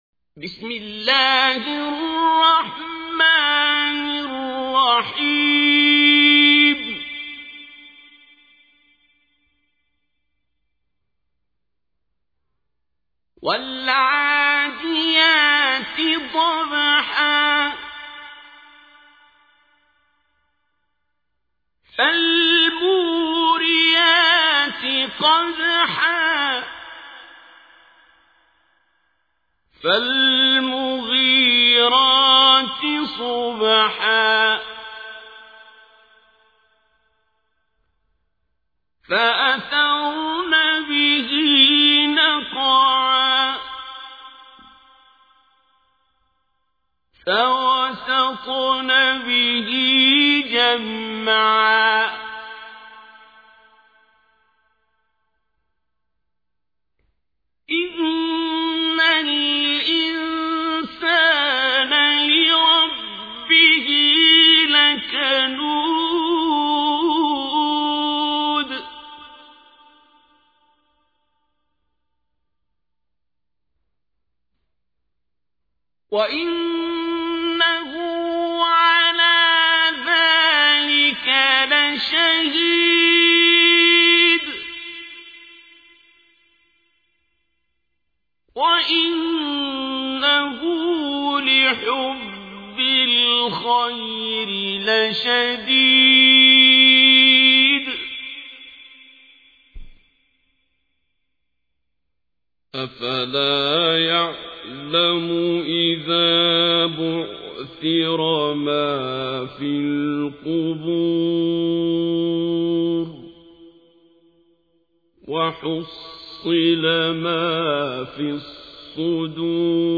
تحميل : 100. سورة العاديات / القارئ عبد الباسط عبد الصمد / القرآن الكريم / موقع يا حسين